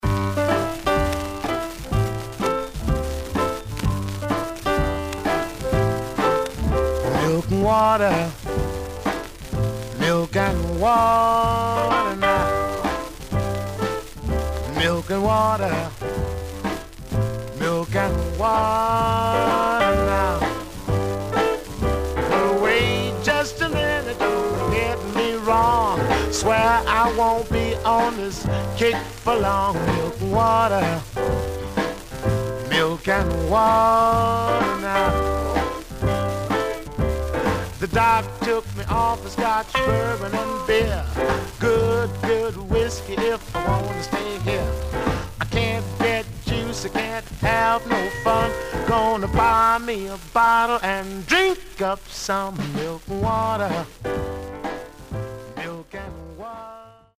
Condition Some surface noise/wear Stereo/mono Mono
Rythm and Blues